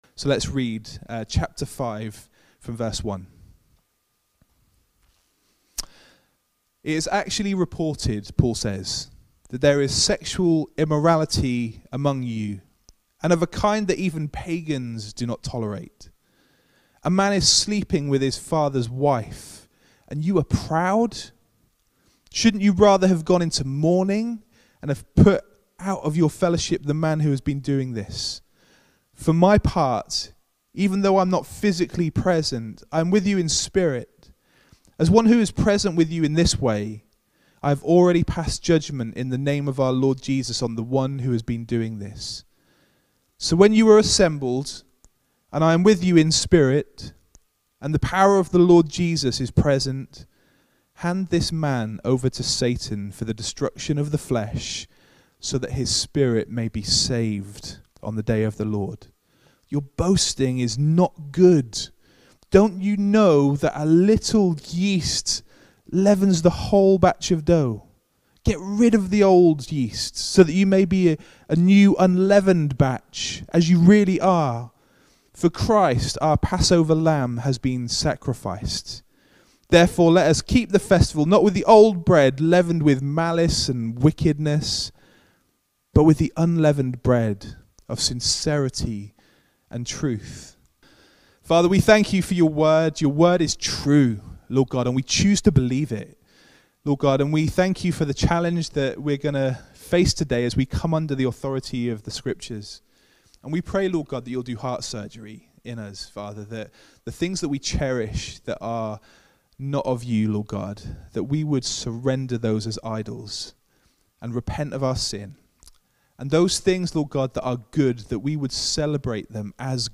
Feb 28, 2021 1 Corinthians – ‘Sexual Purity’ MP3 SUBSCRIBE on iTunes(Podcast) Notes Sermons in this Series The church in Corinth had a problem with sexual immorality, so much so that Paul had to write to them and tell them to put their house in order!